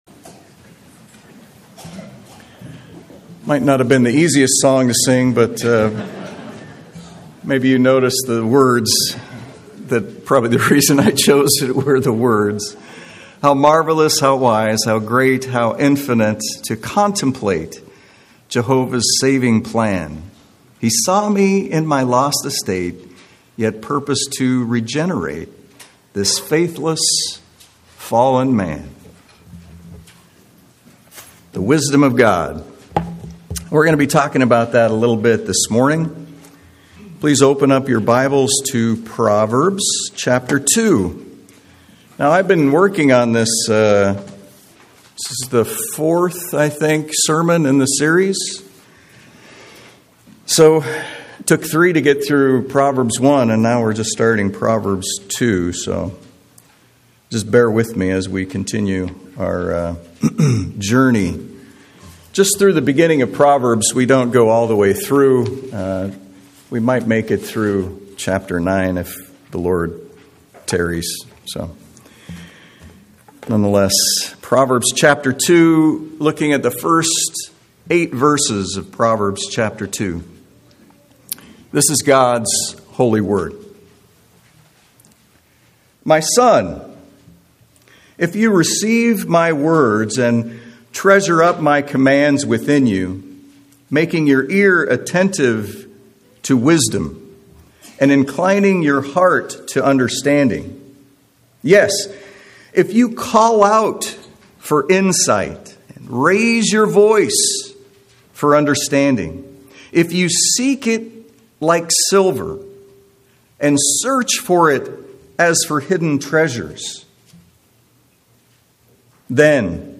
Unfortunately, we were only able to record the first 10 minutes of this sermon.